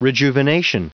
Prononciation du mot rejuvenation en anglais (fichier audio)
Prononciation du mot : rejuvenation